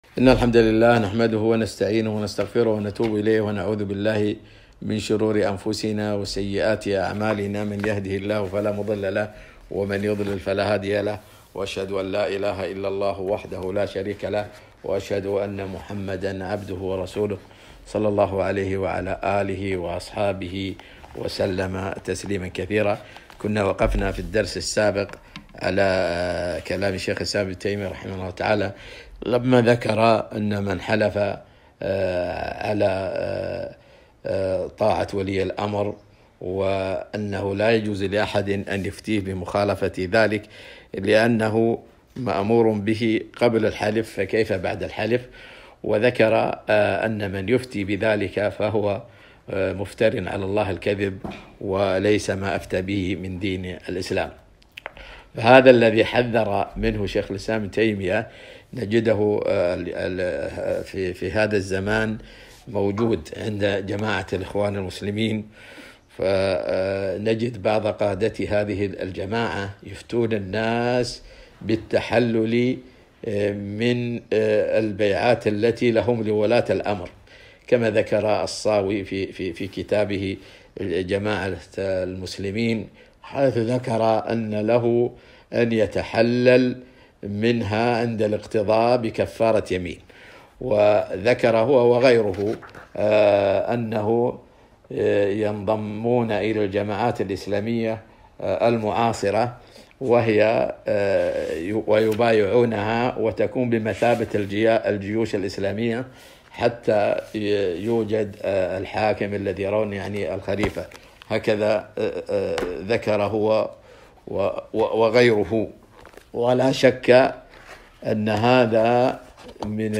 محاضرة - هم العدو فاحذرهم